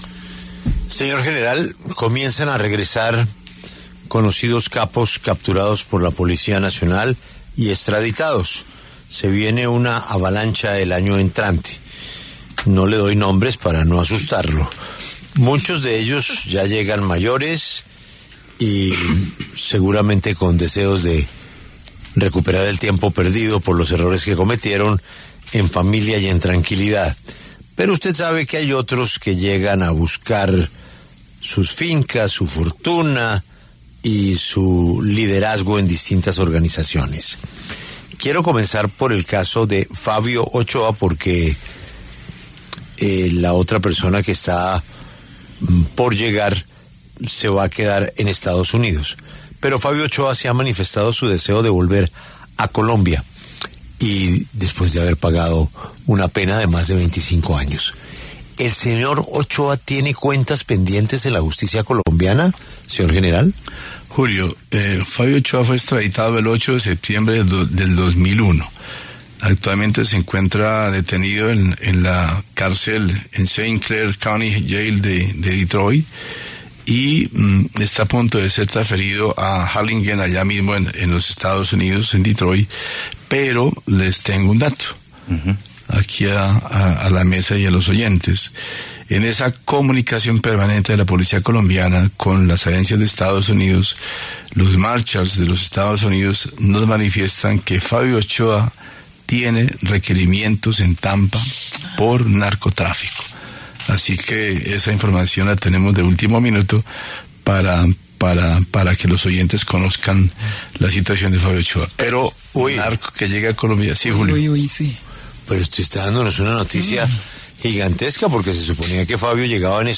En diálogo con La W, el general William René Salamanca, director de la Policía Nacional, se refirió a las cuentas que Fabio Ochoa tiene pendientes con la justicia.